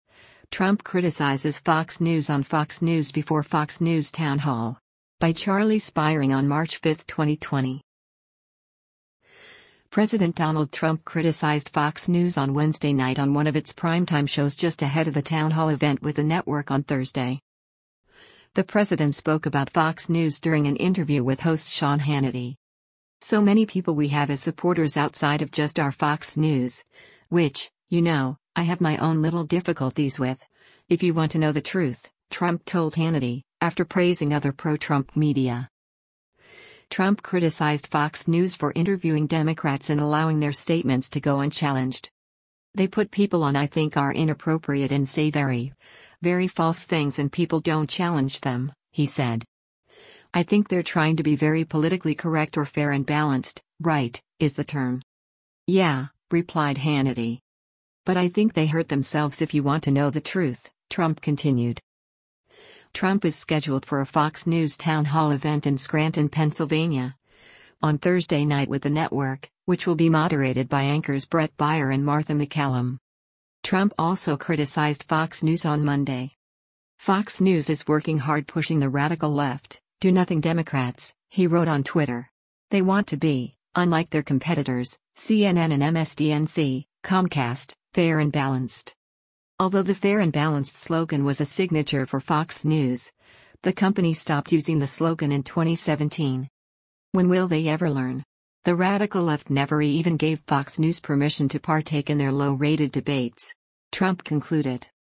The president spoke about Fox News during an interview with host Sean Hannity.